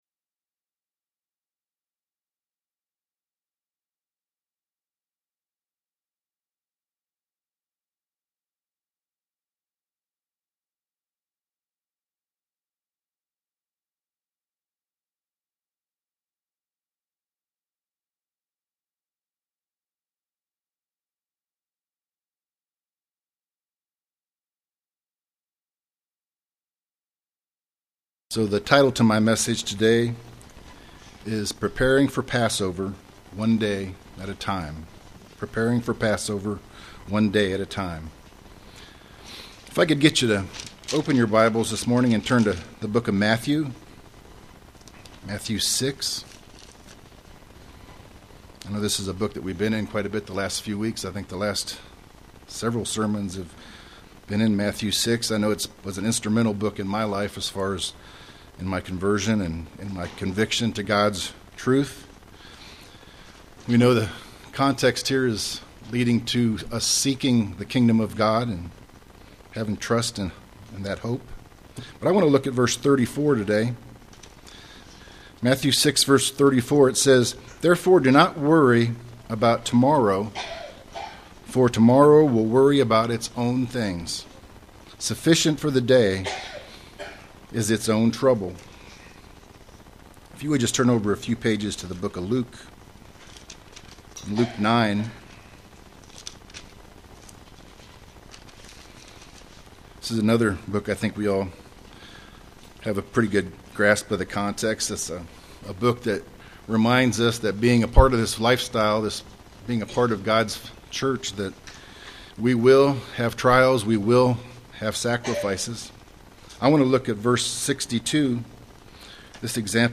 Print Preparing For Passover One Day at a Time UCG Sermon Studying the bible?